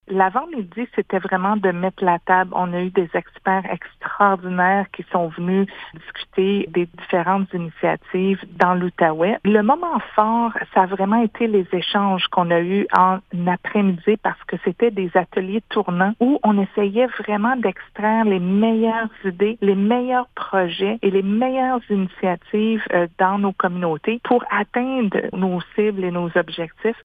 À l’origine de cette initiative en Outaouais, la députée fédérale de Pontiac, Sophie Chatel, raconte comment cette journée de discussions s’est déroulée :